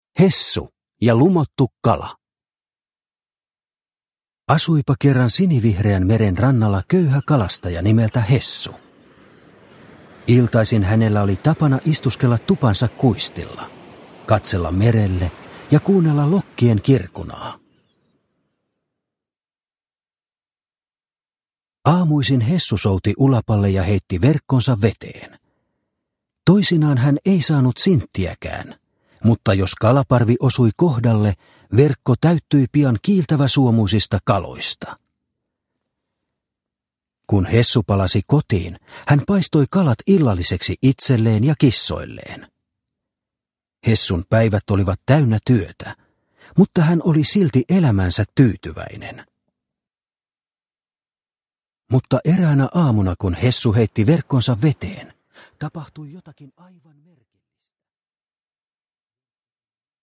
Hessu ja lumottu kala – Ljudbok – Laddas ner